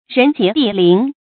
rén jié dì líng
人杰地灵发音